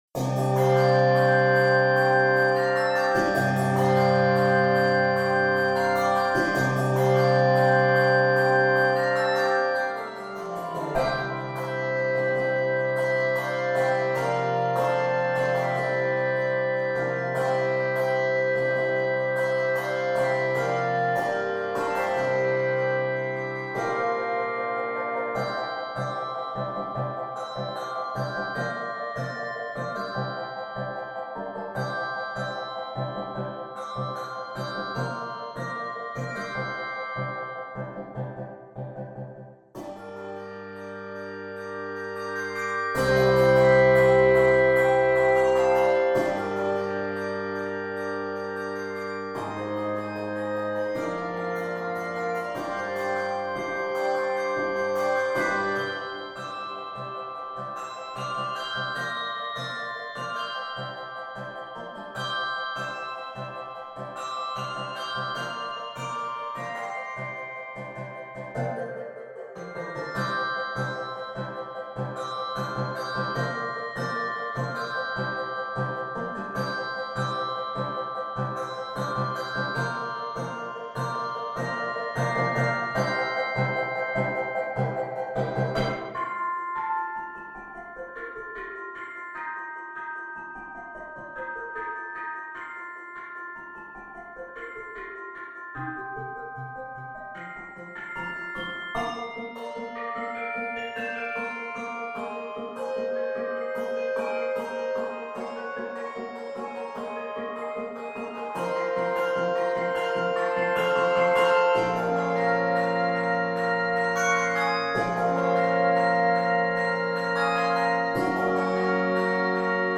Review: Tempo marking reads: Explosively!